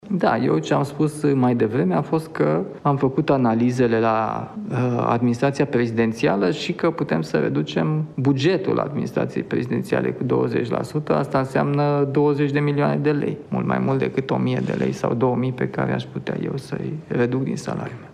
Administraţia Prezidenţială îşi va diminua bugetul propriu cu 20% la rectificarea bugetară, a afirmat, luni, preşedintele Nicuşor Dan, în cadrul unei conferinţe de presă, în contextul în care Guvernul pregătește un al doilea pachet de măsuri fiscale.
Președintele Nicușor Dan a susținut luni o conferință de presă la Palatul Cotroceni.